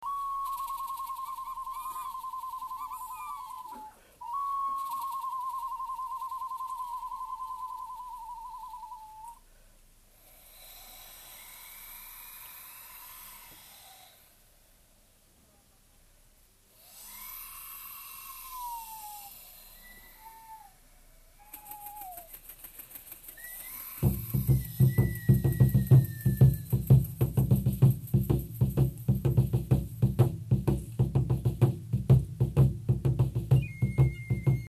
ネットで見つけたホロフォニクスで録音されたと思われる音源。